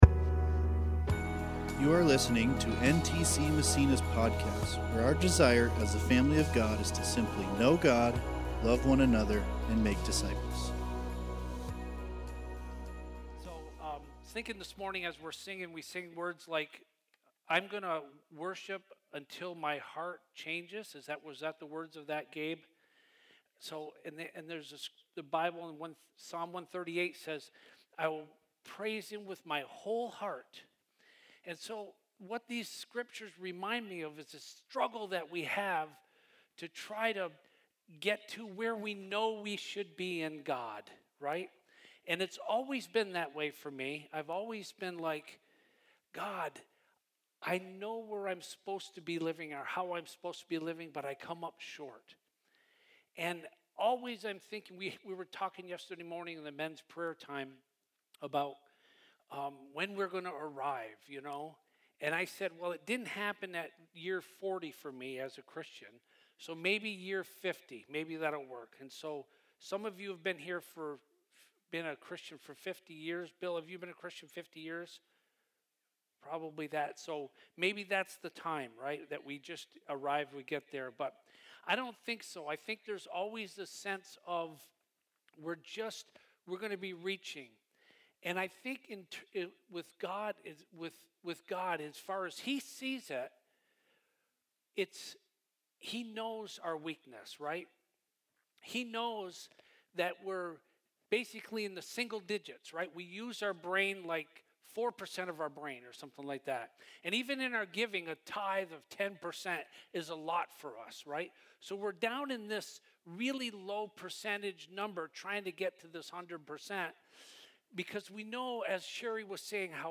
2024 Growing W.3- Work Preacher